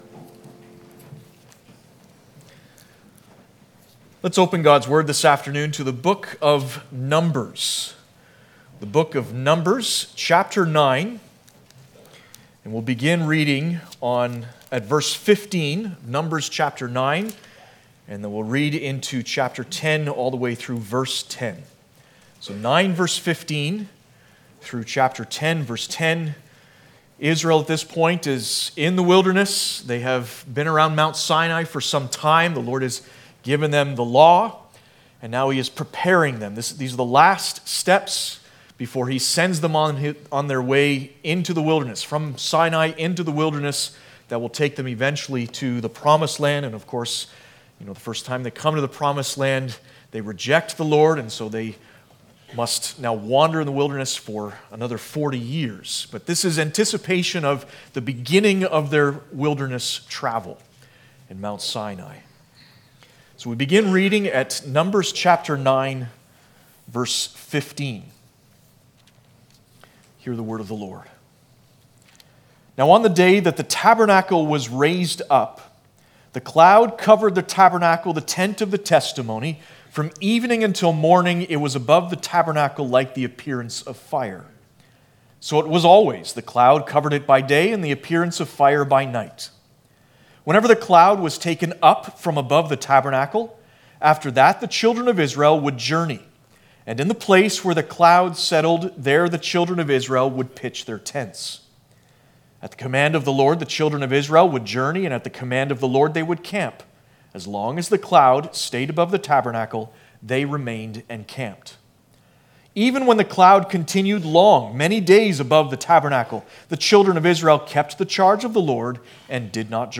Passage: Numbers 9:15-10:10 Service Type: Sunday Afternoon